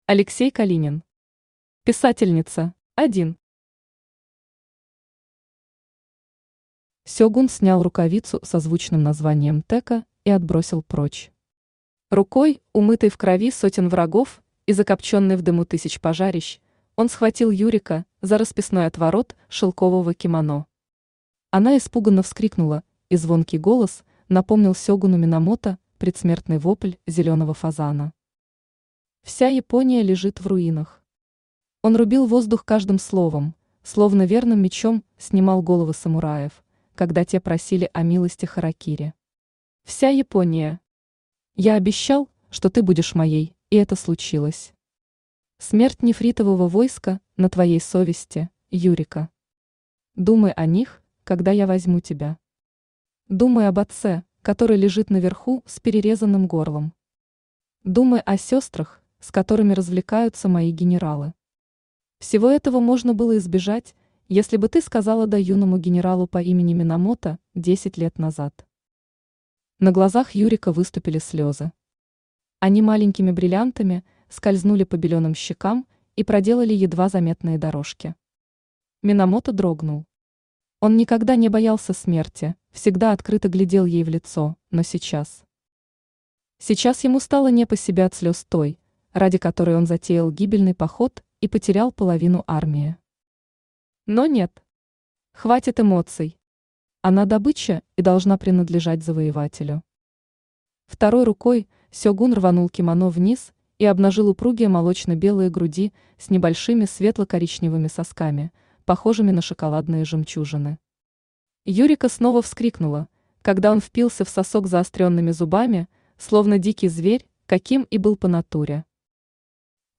Аудиокнига Писательница | Библиотека аудиокниг
Aудиокнига Писательница Автор Алексей Калинин Читает аудиокнигу Авточтец ЛитРес.